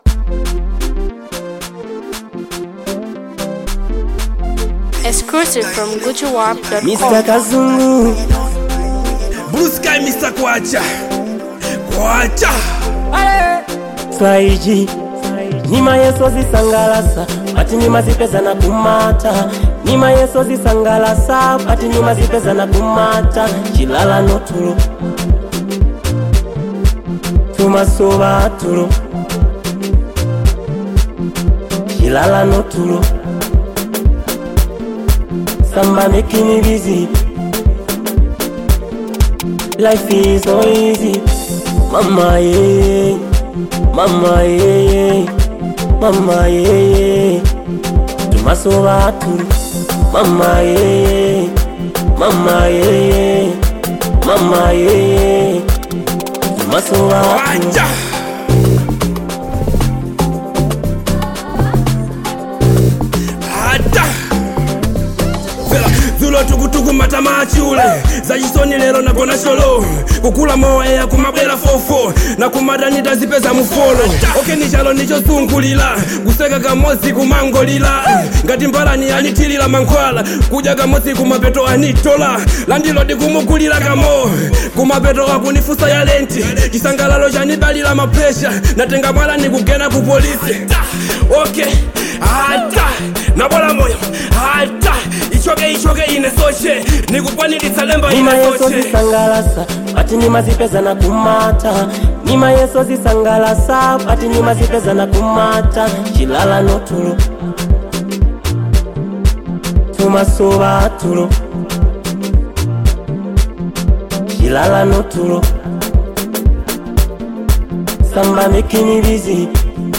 boasts exceptional production quality
Experience the energetic rhythms and melodies